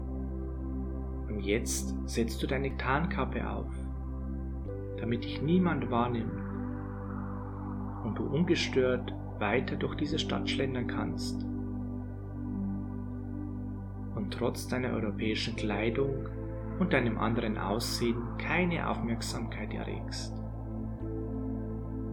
Unsere professionell aufgenommenen Hypnosessitzungen führen Sie in einen tiefen Entspannungszustand, in dem Sie sich von Sorgen und Anspannungen befreien können.
geführte Hypnose für Selbstbewusstsein und Intuition
S6002-Der-Zauberlehrling-maennliche-Stimme-Hoerprobe.mp3